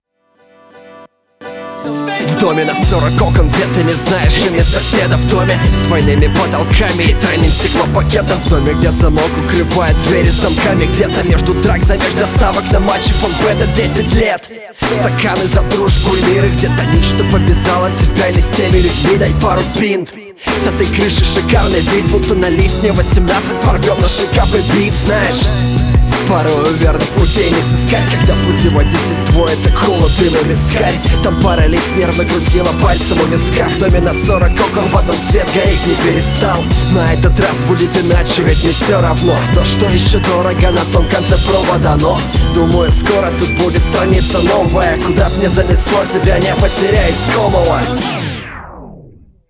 Читка прочувствованная, хорошо доносит мысль и вызывает доверие.